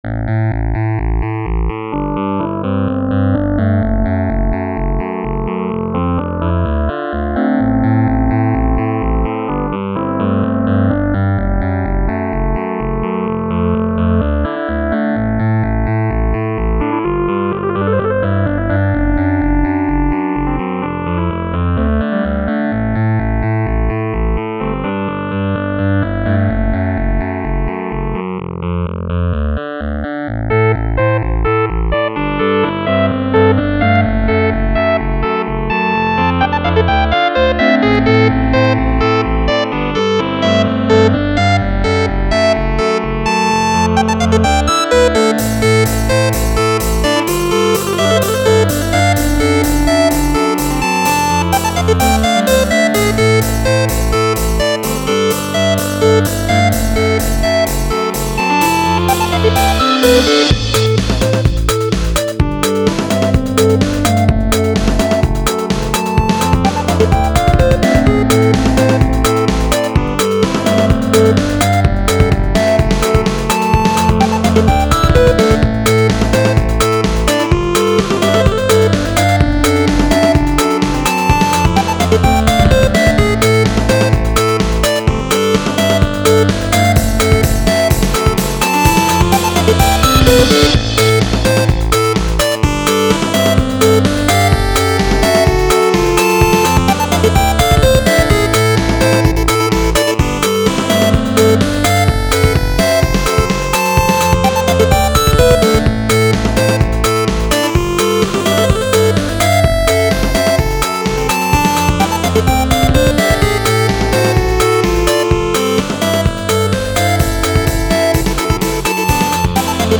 Fooled around in FL a long time ago, made some generic background music.